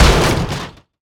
freezerHit3.wav